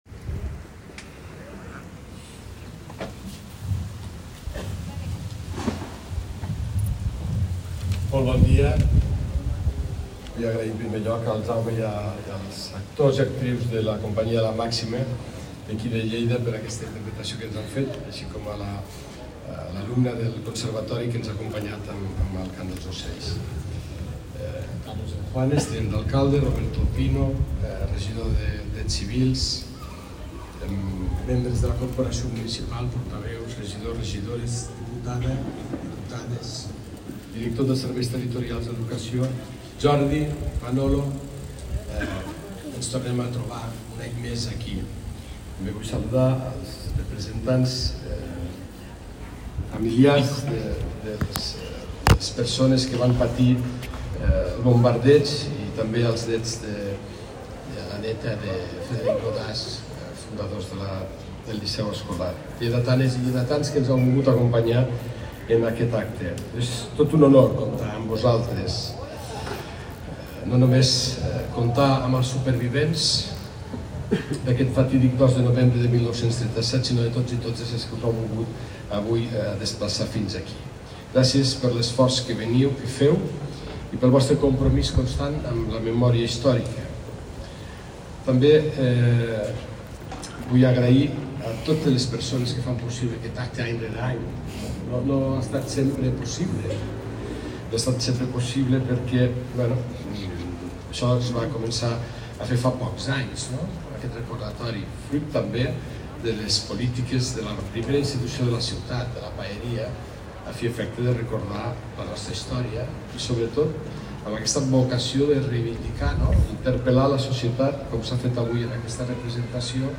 Lleida ha recordat aquest diumenge les víctimes del bombardeig del Liceu Escolar amb un acte institucional i una ofrena floral davant l’escultura “Memòria, Dignitat i Vida”, en què han participat l’alcalde Fèlix Larrosa, membres del govern municipal, exalumnes del centre i familiars dels afectats.